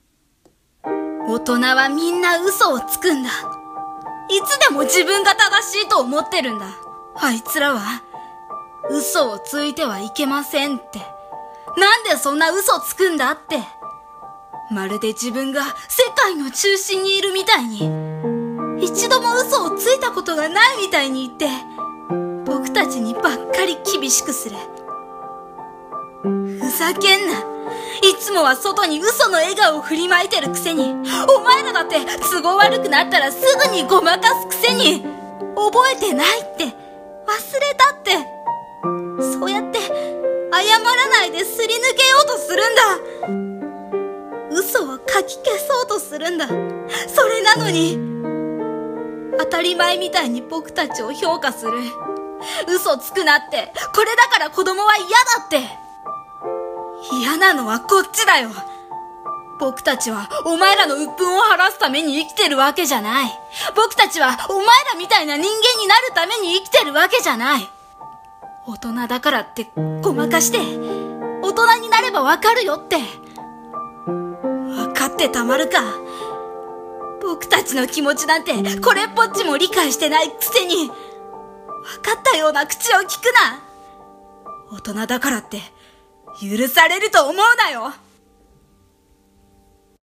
【一人声劇】